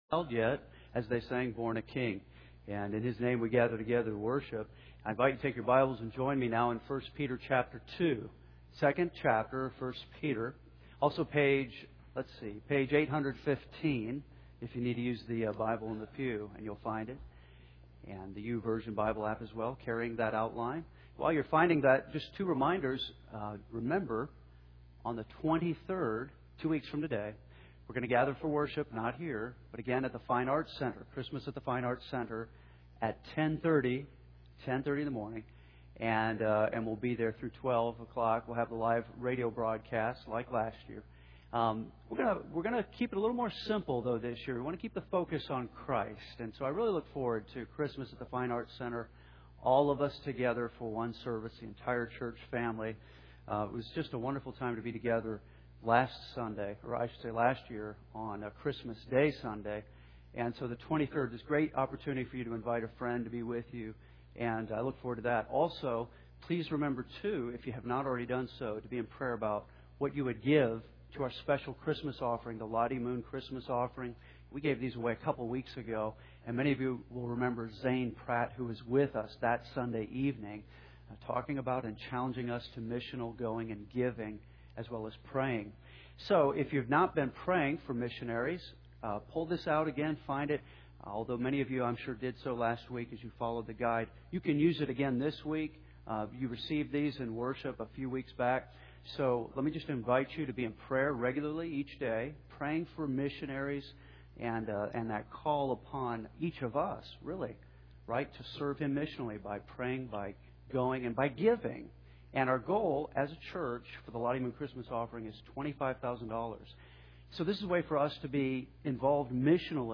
Henderson’s First Baptist Church, Henderson